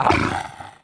Npc Raccoon Wakes Sound Effect
npc-raccoon-wakes.mp3